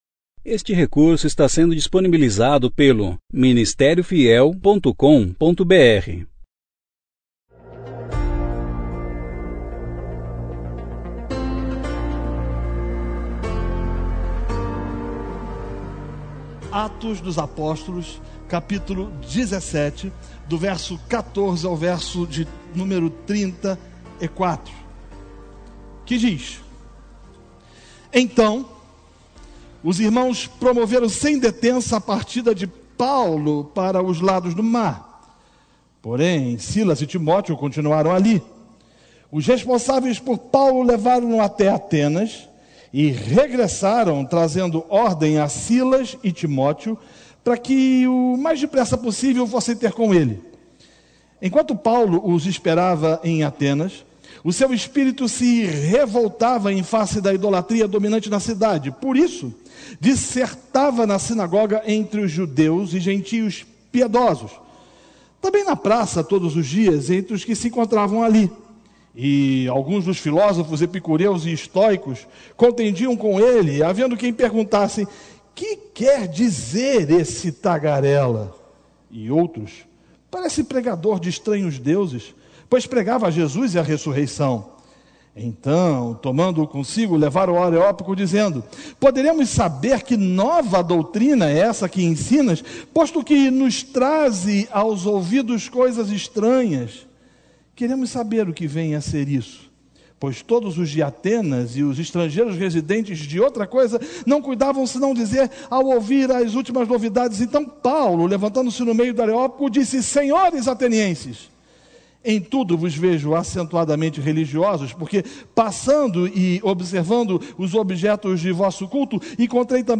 Conferência Fiel